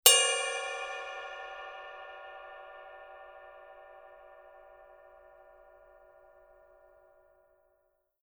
Sustain: assez long
Caractère du son: Brillant, dur, énergique. Spectre assez étroit, mix légèrement complexe. Sensations lourdes. Ping vitreux, puissant qui devient plus sec, plus brut et boisé dans la zone non tournée et plus expressif, croustillant et riche dans la zone tournée. Halo assez brut et terreux qui est très serré et contrôlé. Cloche lourde et perçante. Cymbale ride tranchante et variée avec des zones de jeux distinctes pour une utilisation articulée dans les sets lourds.
signature_20_duo_ride_bell.mp3